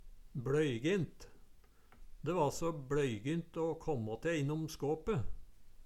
DIALEKTORD PÅ NORMERT NORSK bLøygint vrangt Eksempel på bruk De va så bLøygint å kåmmå te innom skåpe Tilleggsopplysningar Kjelde